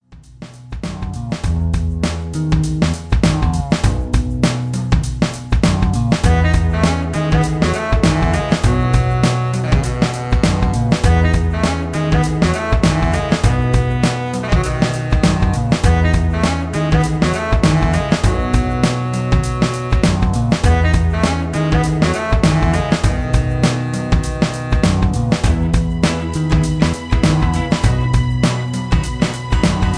karaoke